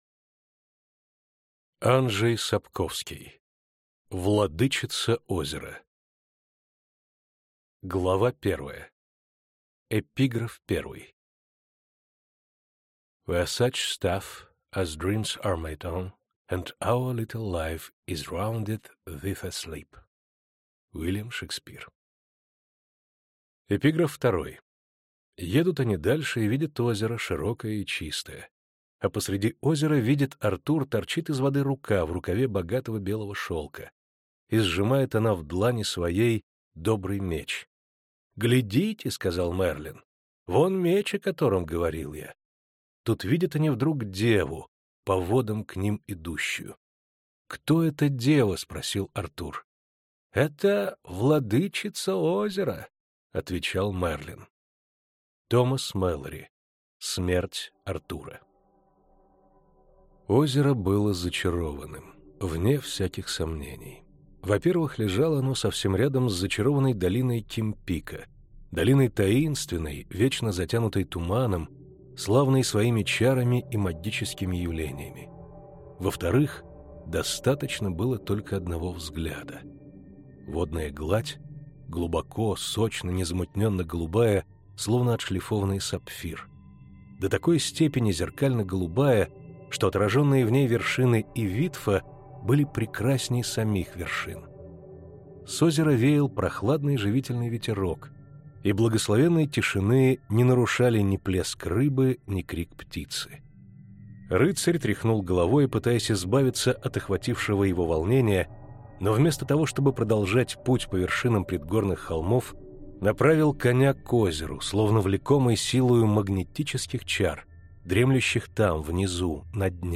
Аудиокнига Владычица Озера | Библиотека аудиокниг